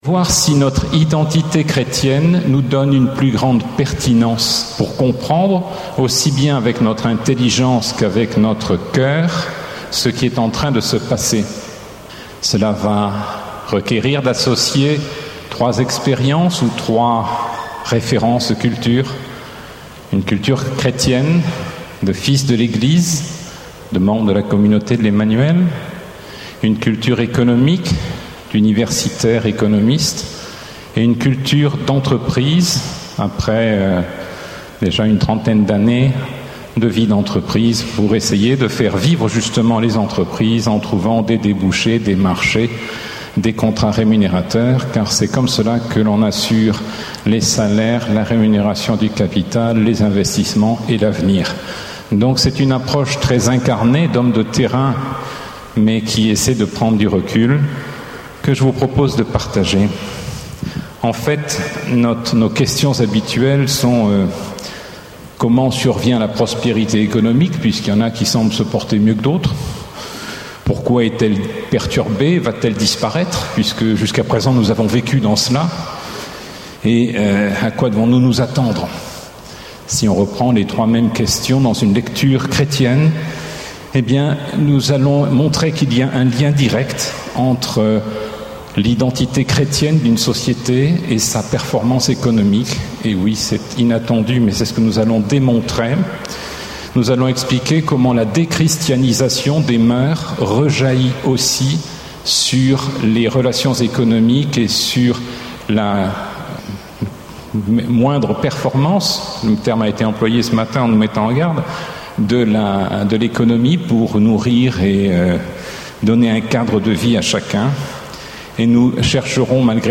Enseignement
Session 25 - 35 ans : L'Amour me presse (14 au 18 juillet 2012)